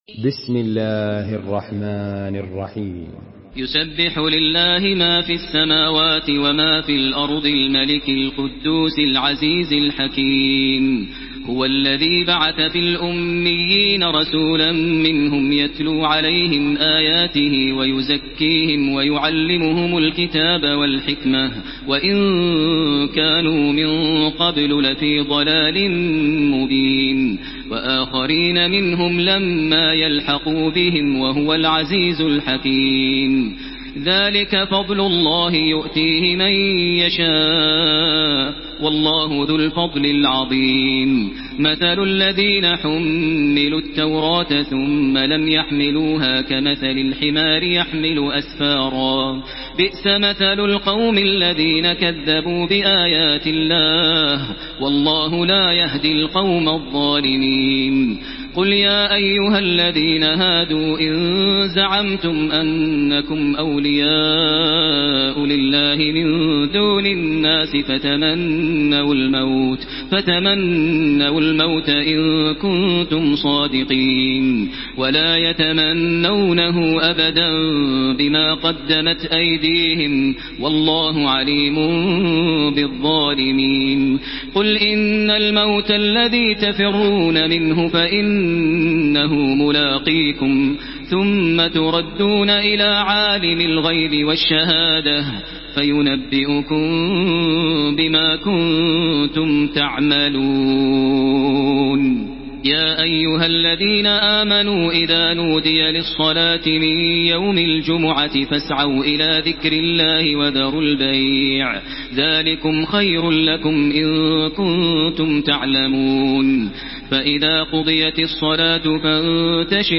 تحميل سورة الجمعة بصوت تراويح الحرم المكي 1433